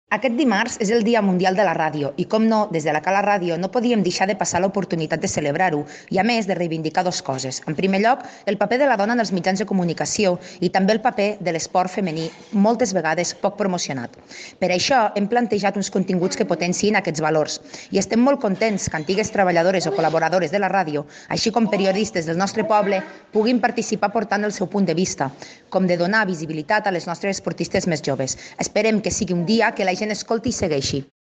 Anunci